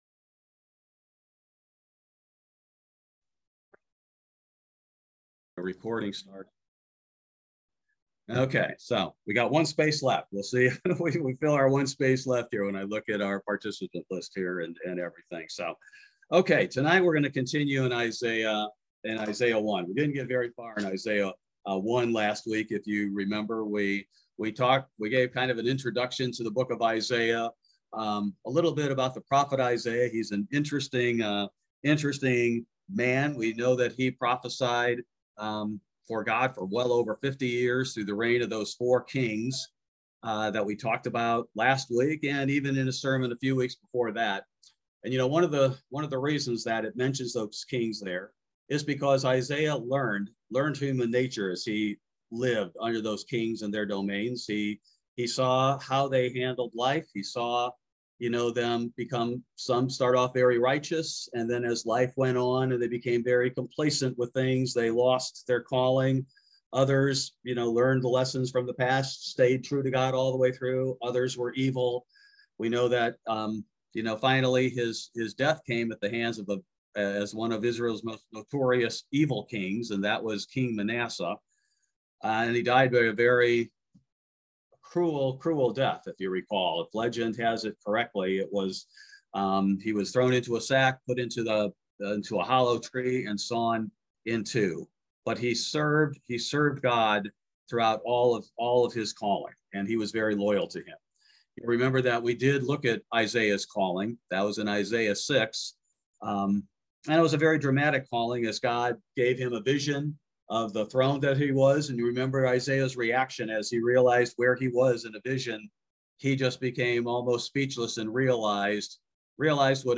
Bible Study: June 22, 2022